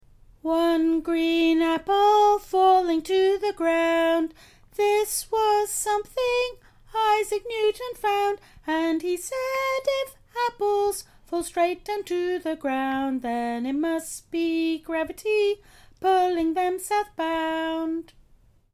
To the tune of children's nursery rhyme